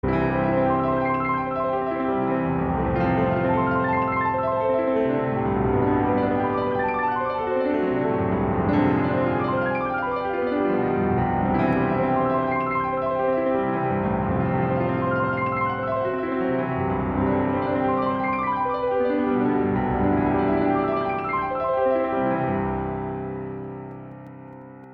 Klassik
Neue Musik
Sololiteratur
Klavier (1)